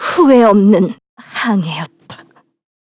Miruru_L-Vox_Dead_kr.wav